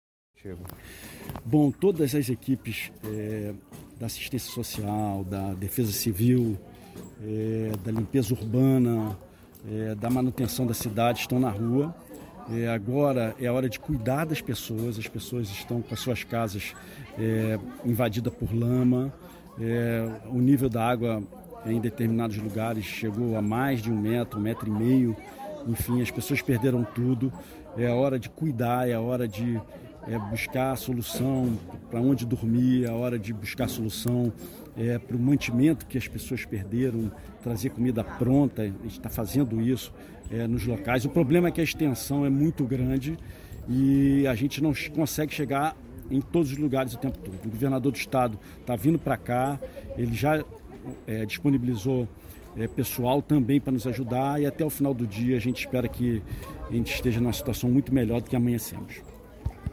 O prefeito de Nova Iguaçu, Rogério Martins Lisboa, conversou com a Super Rádio Tupi, na tarde deste sábado (2), em entrevista ao programa Super Futebol Tupi, e comentou sobre a situação difícil vivida pelos moradores da Baixada Fluminense, após as chuvas fortes dos últimos dias no Rio de Janeiro.